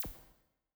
ENE Zap.wav